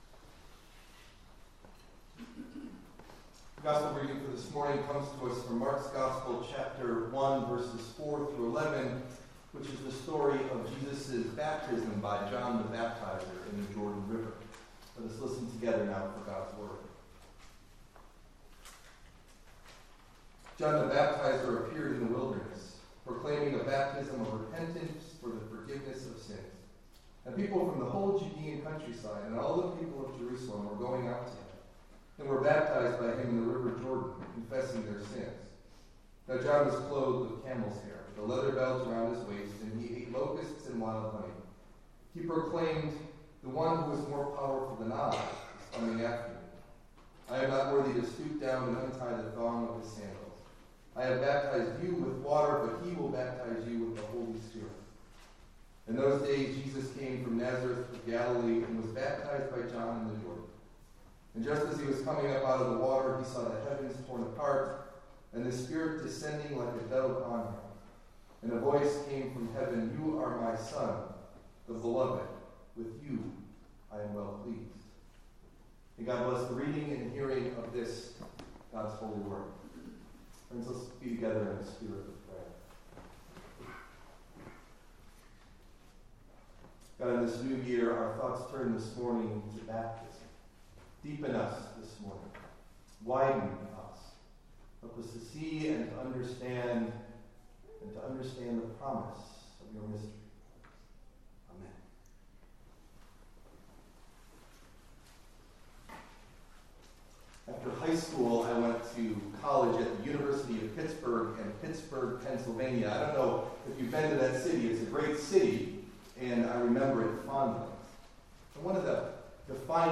Delivered at: The United Church of Underhill (UCC and UMC)